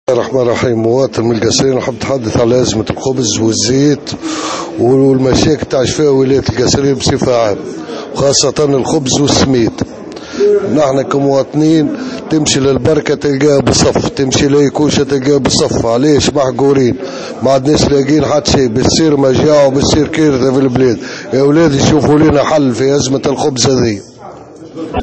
Les habitants de Kasserine sont intervenus aujourd’hui au micro de Tunisie Numérique pour exprimer leur colère face à la pénurie de certains matières premières dont la semoule et la farine.